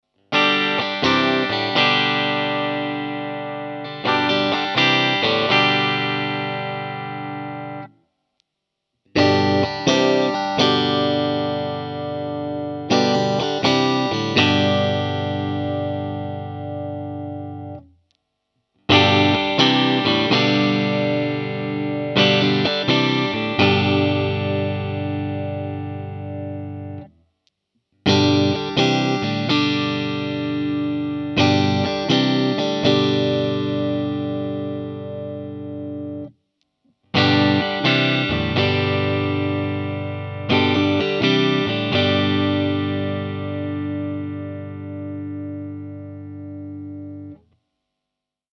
2. Godin Triumph Sparkle Blue Clean 1,14 Мб
От бриджа до нека 5 примеров звучания
godin_triumph_clean.mp3